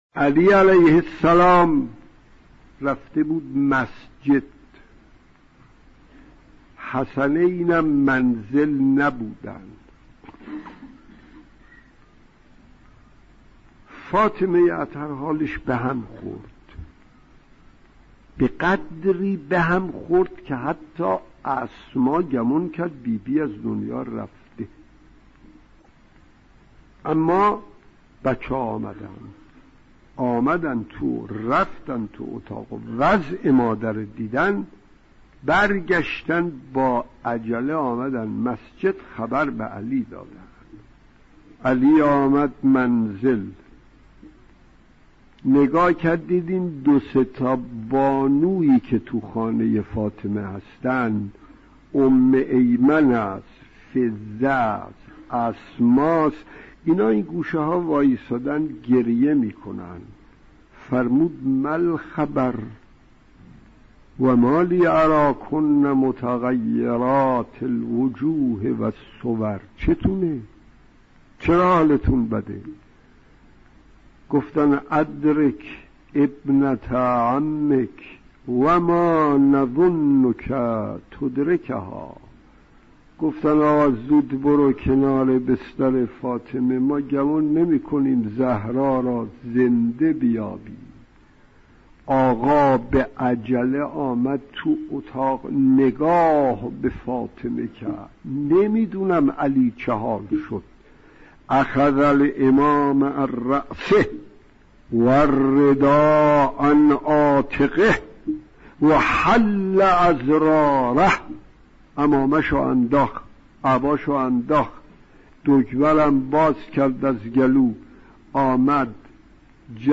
بیان کیفیت با خبر شدن امیرالمومنین(ع) از شهادت حضرت زهرا(س) توسط مرحوم حجت السلام فلسفی (3:11)